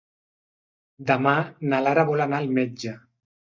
Read more personal article used before feminine given names instead of the definite article la Frequency B2 Pronounced as (IPA) /nə/ Etymology From the final syllable of Latin domina (“Lady”).